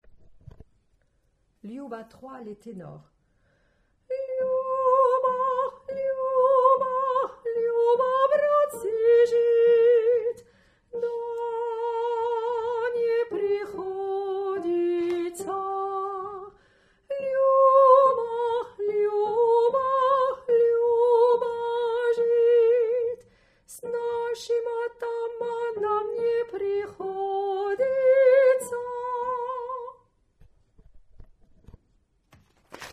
Tenor
luba3_Tenor.mp3